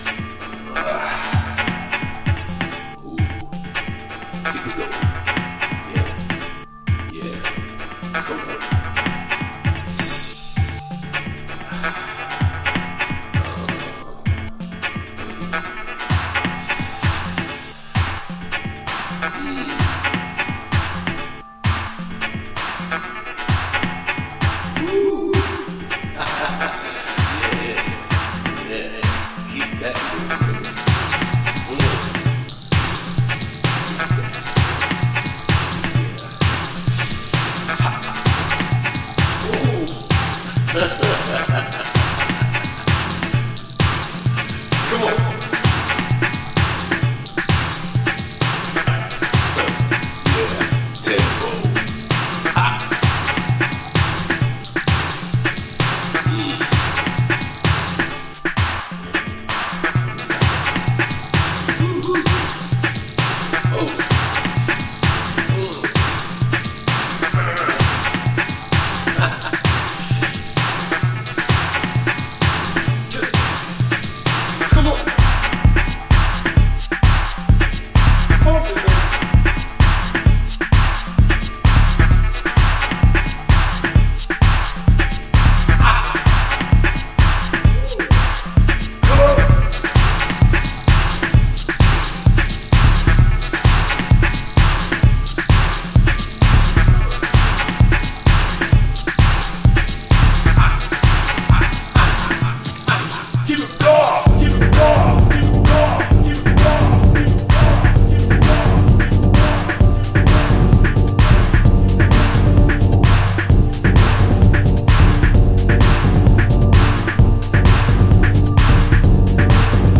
House / Techno
タイトルとその風貌に偽りなしのマッドネス・ハウストラック。フロアでの驚異的なパワーを発揮する一枚です。